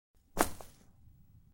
Шум упавшего цветка на землю